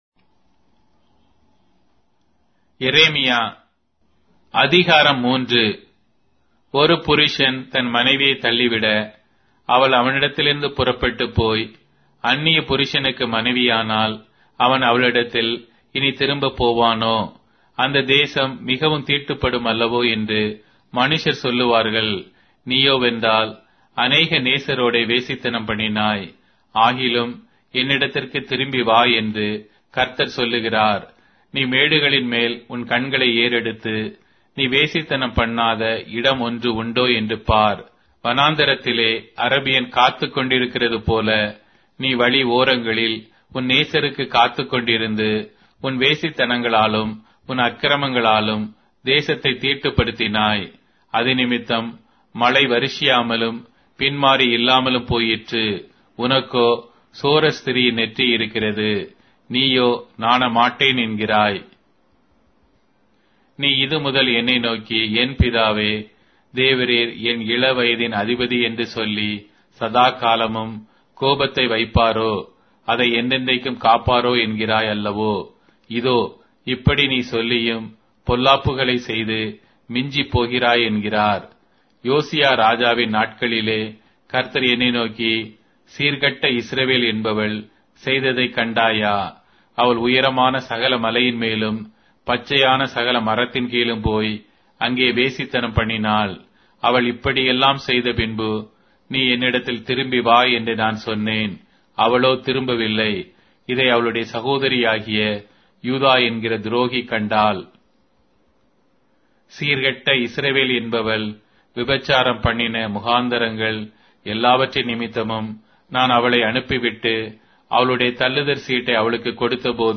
Tamil Audio Bible - Jeremiah 21 in Rv bible version